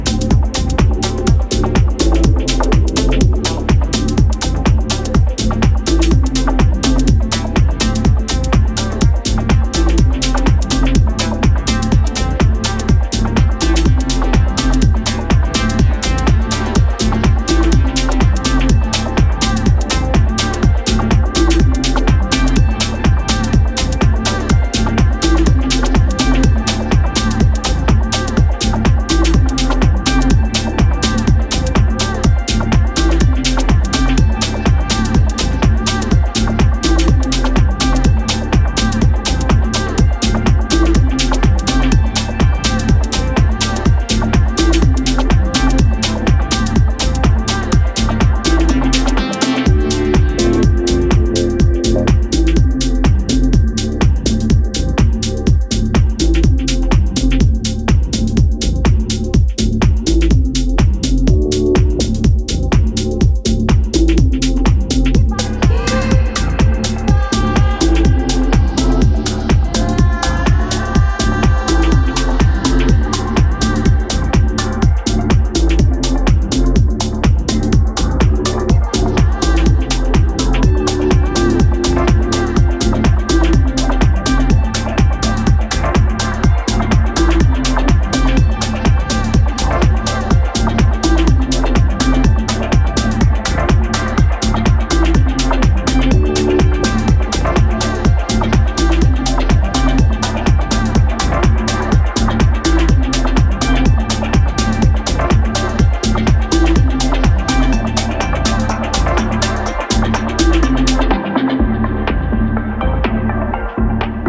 five tracks across house, electro and breaks